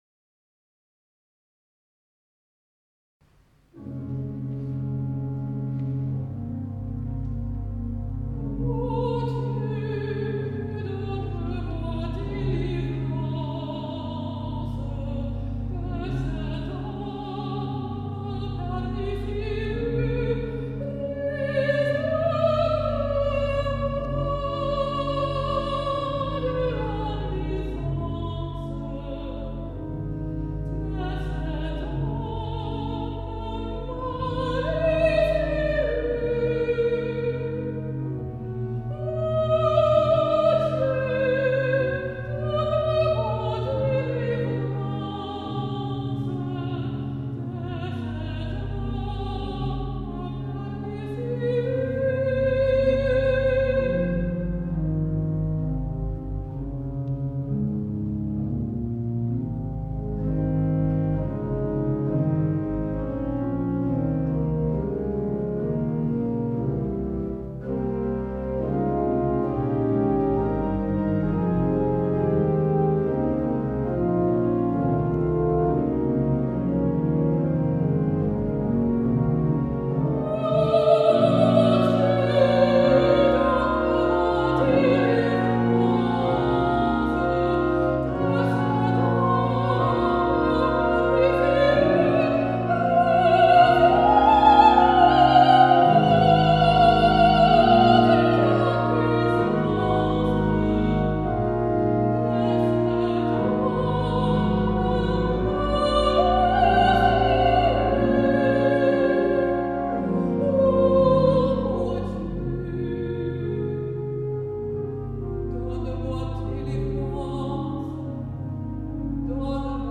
A.Honegger – Psalm 140 für Mezzo und Orgel / 2008